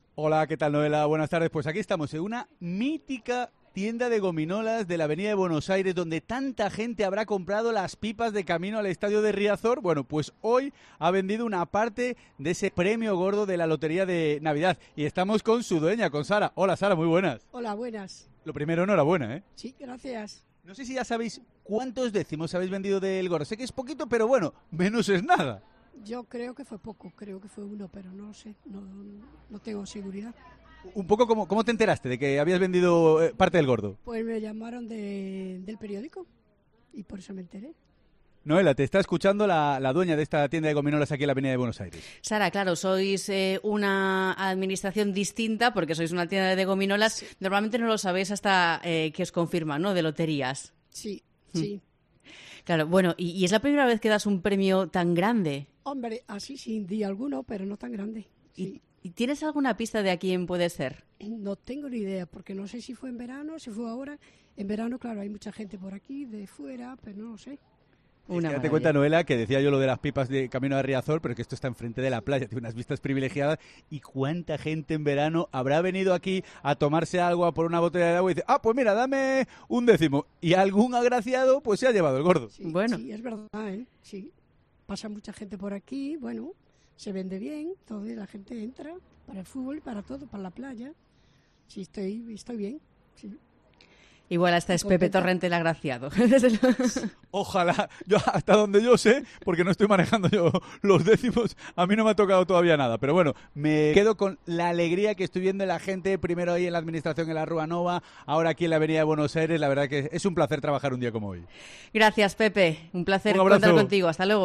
en directo desde la Avenida de Buenos Aires